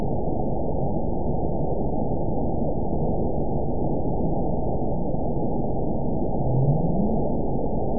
event 921354 date 05/11/24 time 07:14:31 GMT (12 months ago) score 9.53 location TSS-AB02 detected by nrw target species NRW annotations +NRW Spectrogram: Frequency (kHz) vs. Time (s) audio not available .wav